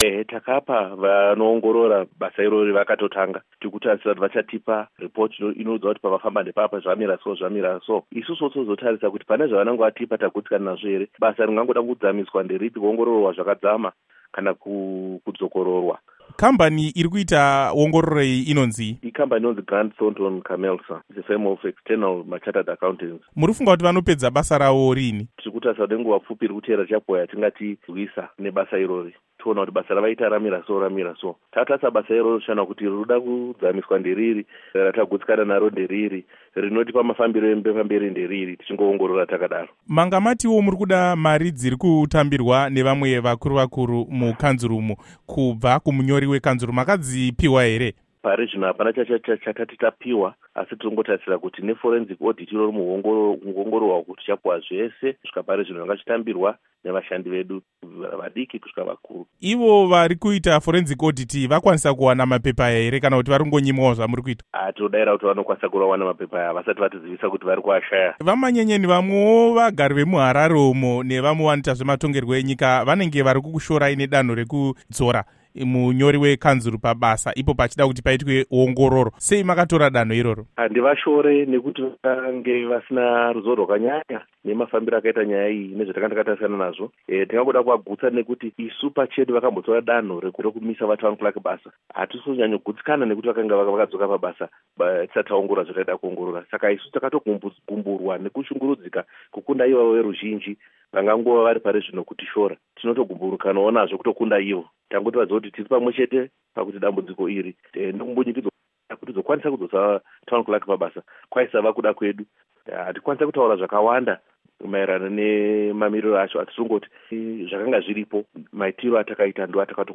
Hurukuro naVaBernard Manyenyeni